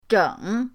zheng3.mp3